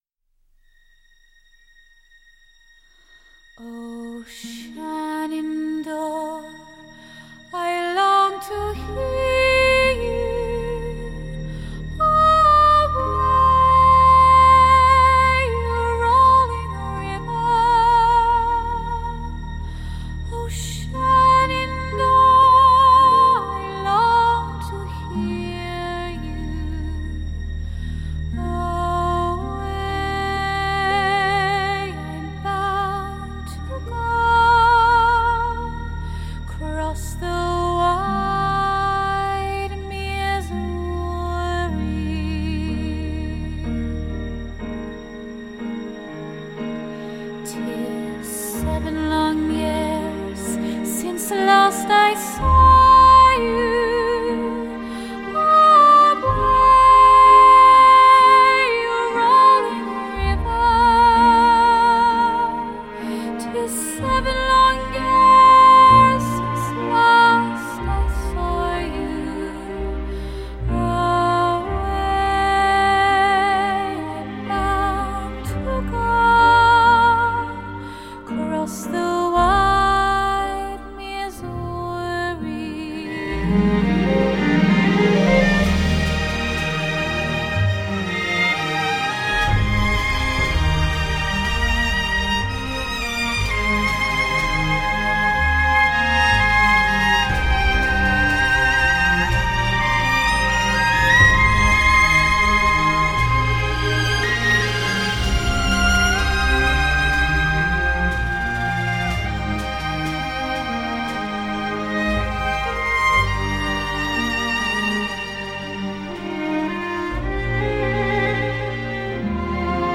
【天籟般的純淨美聲】